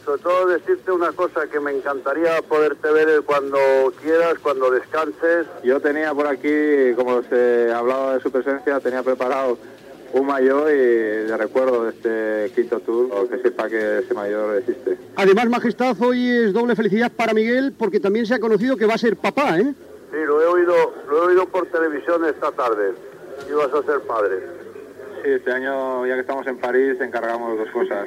Fragment d'una conversa entre el ciclista Miguel Indurain i el Rei Juan Carlos I
Esportiu
FM